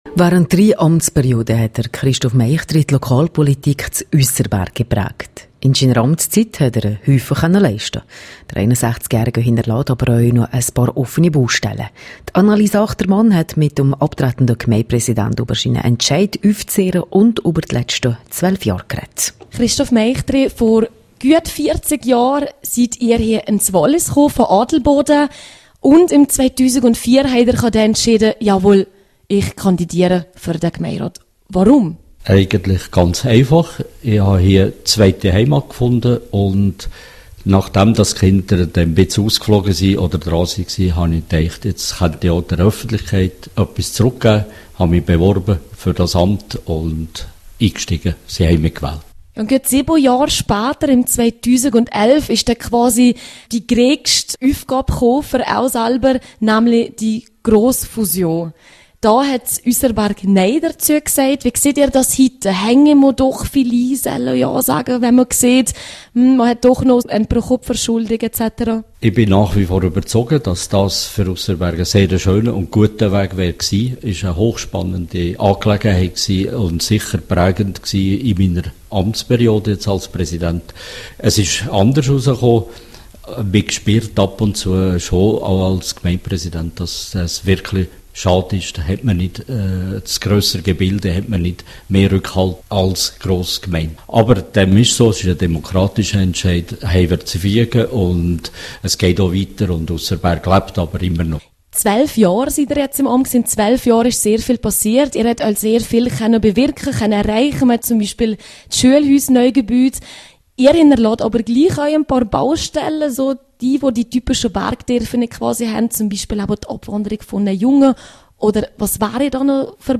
Vor allem die Abstimmung über die Grossfusion Visp prägte die Amtszeit des Aussenberger Gemeindepräsidenten Christoph Meichtry, wie er im rro-Interview sagt.